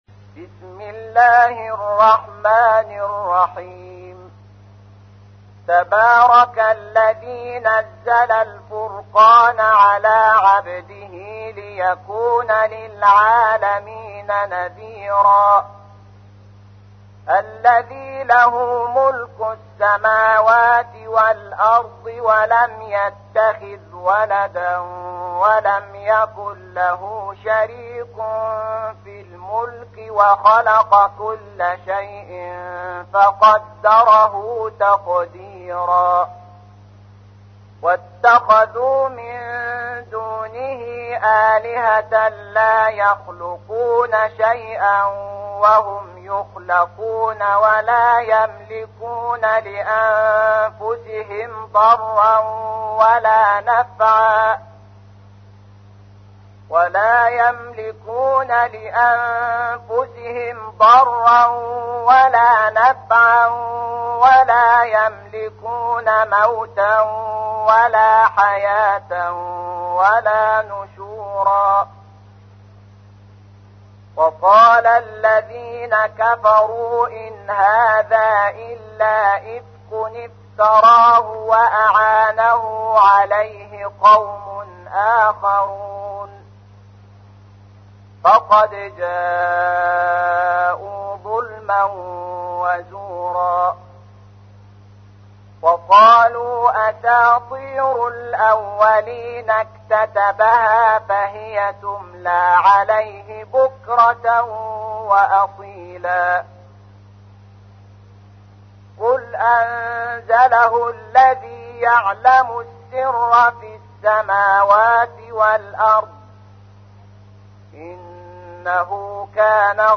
تحميل : 25. سورة الفرقان / القارئ شحات محمد انور / القرآن الكريم / موقع يا حسين